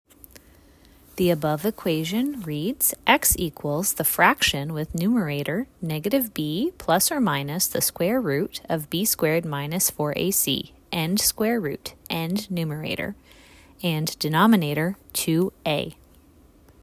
Audio descriptionAudio Description of Equation:
Equation-audio-description.mp3